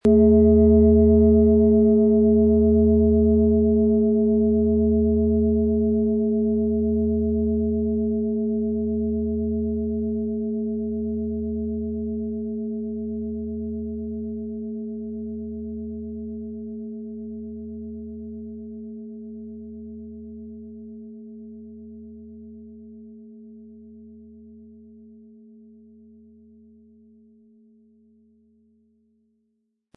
Planetenschale® Körper und Seele verbinden & Sensibel und Einfühlend sein mit Wasserstoffgamma & Mond, Ø 24,5 cm, 1700-1800 Gramm inkl. Klöppel
• Mittlerer Ton: Mond
Der Klöppel lässt die Klangschale voll und harmonisch tönen.
MaterialBronze